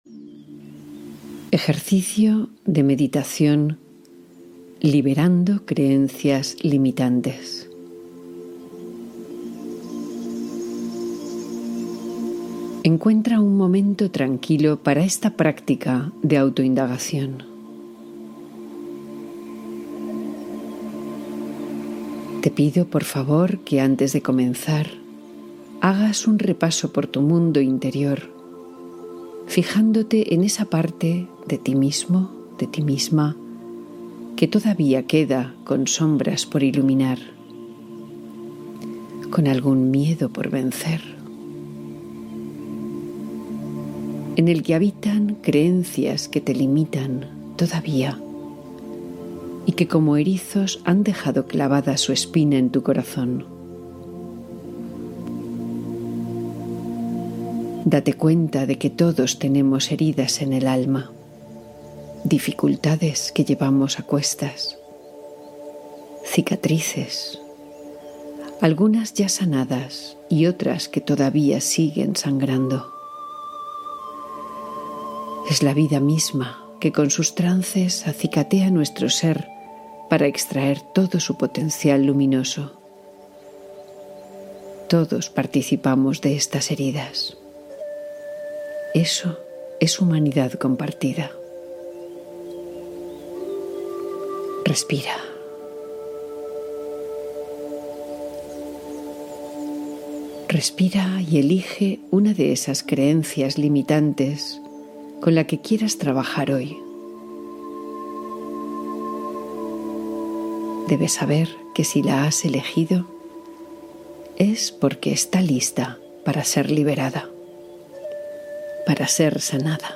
Libera creencias limitantes con esta poderosa meditación guiada